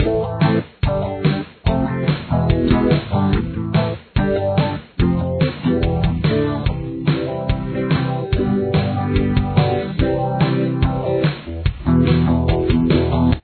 Tempo: 143 beats per minute
Key Signature: A minor
Rhythm Guitar